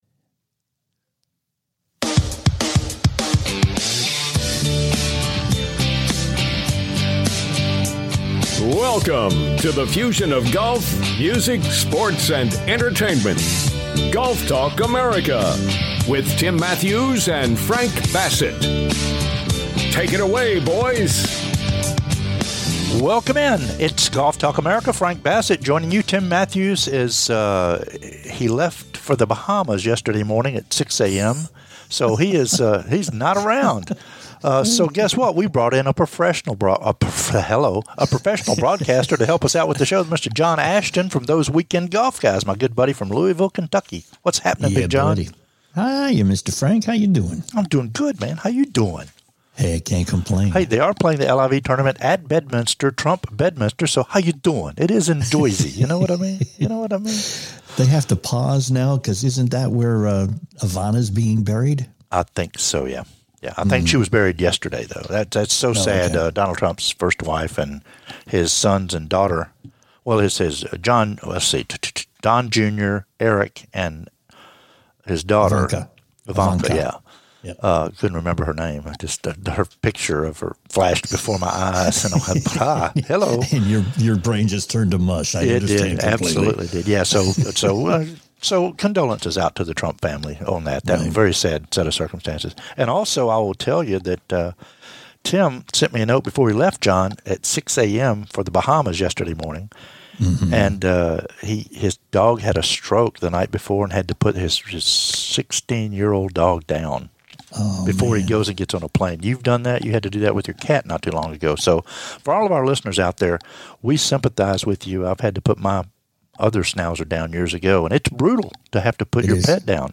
a rambling discussion on a number of topics